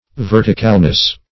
Verticalness \Ver"ti*cal*ness\, n.
verticalness.mp3